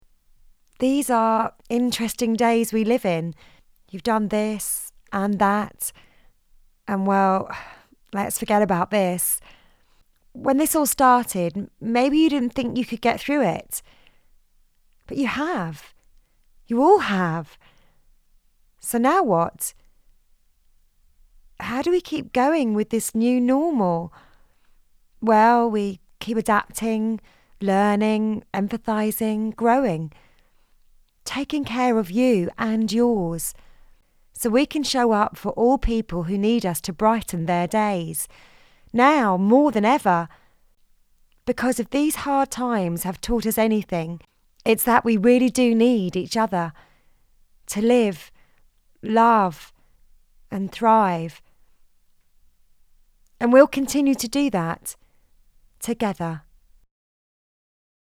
Young adult, natural and conversational